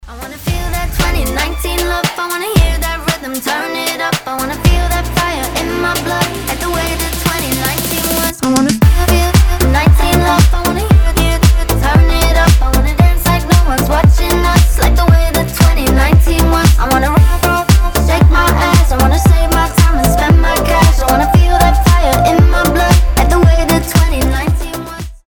• Качество: 320, Stereo
позитивные
ритмичные
EDM
house
bounce